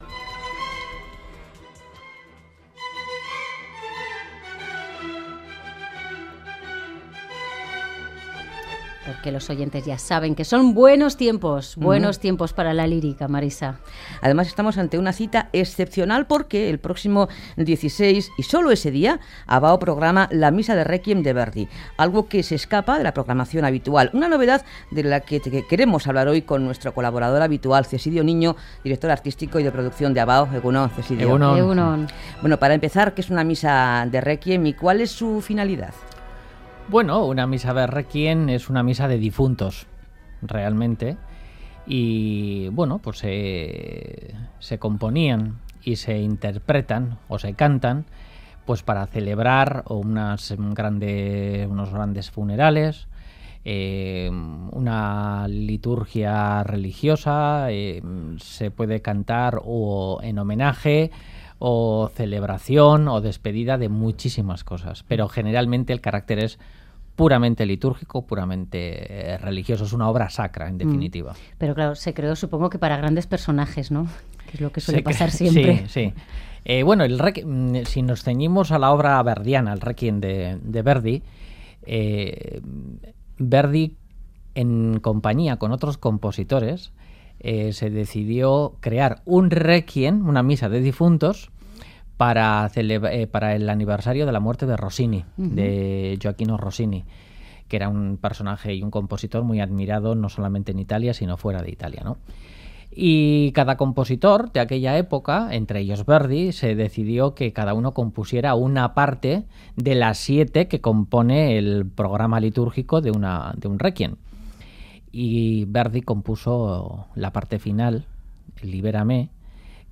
El genio compositivo de Verdi explota en su Misa de Réquiem con un alarde vocal donde el coro brilla con luz propia.